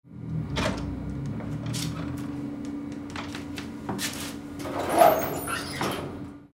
Puerta de un ascensor antiguo
abrir
ascensor
puerta